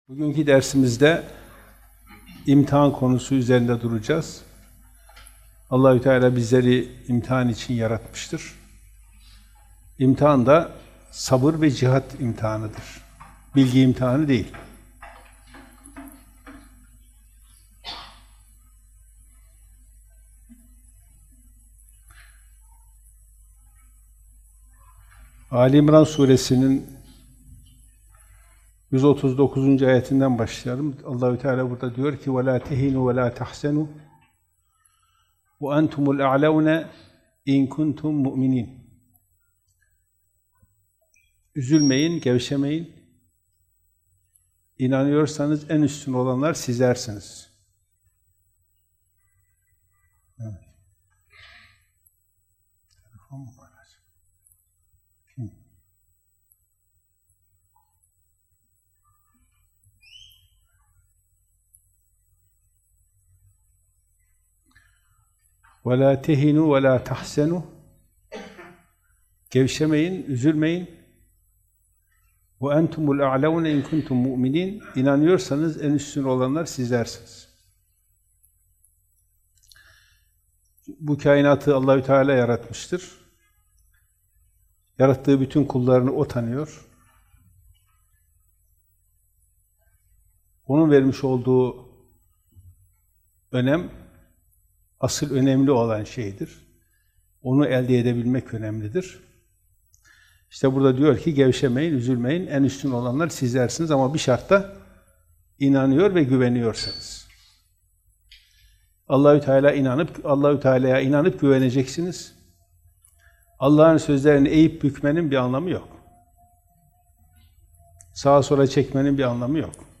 Ayetler – Sabır ve Cihat İmtihanı – Kuran Dersi